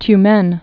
(ty-mĕn)